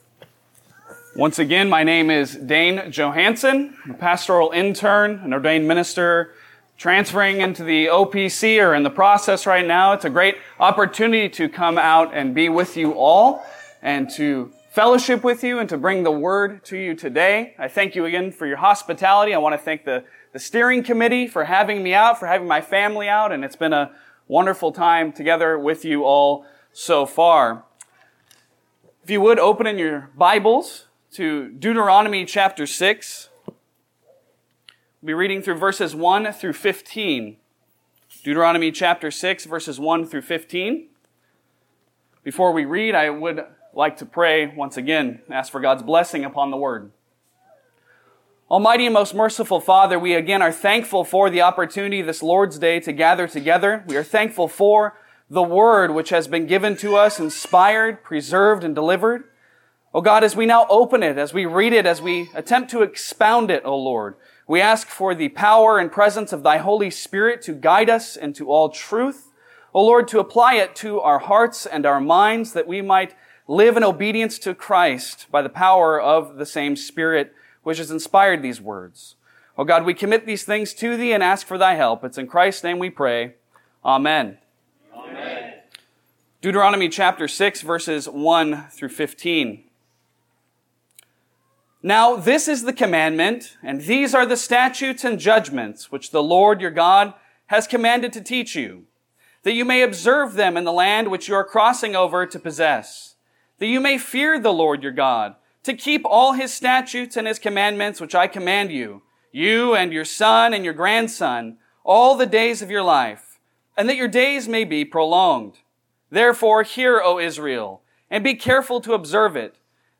Passage: Deuteronomy 6:1-15 Service Type: Sunday Sermon